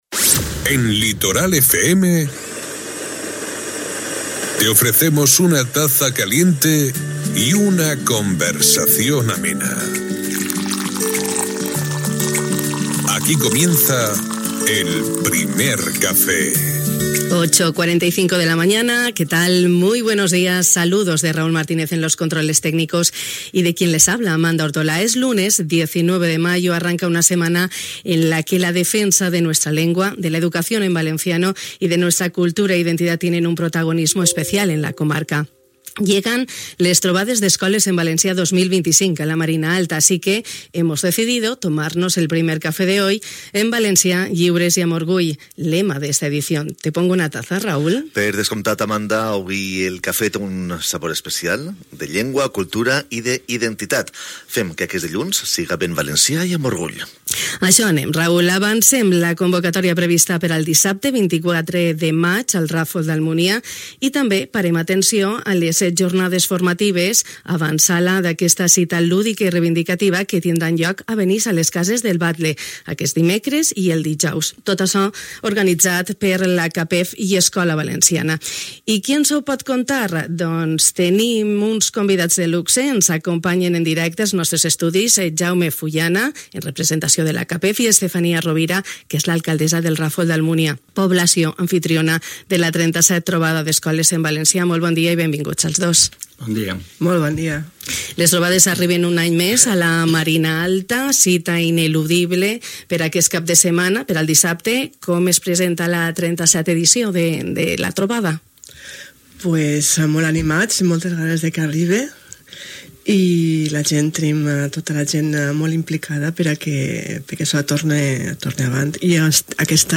Esta mañana en El Primer Café de Radio Litoral hemos hablado de Les Tobades d’Escoles en Valencià 2025 a la Marina Alta. Convocatoria que tendrá lugar este sábado, 24 de mayo, en El Ràfol d’Almunia.